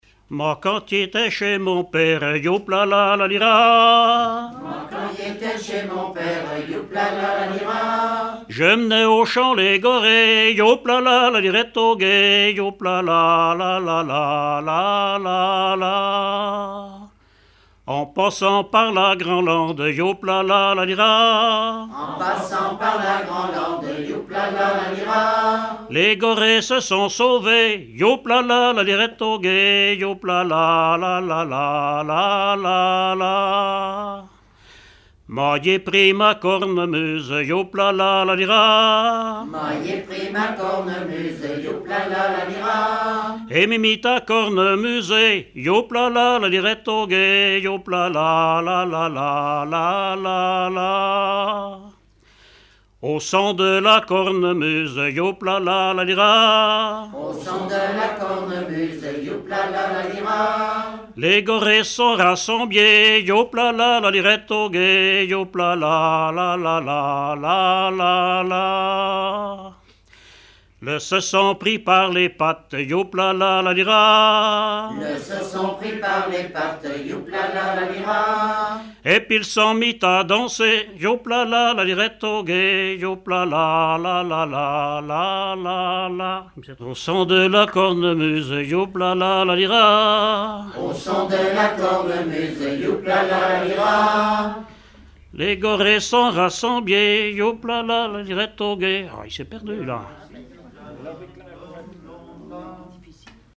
Genre laisse
Répertoire de chansons populaires et traditionnelles
Pièce musicale inédite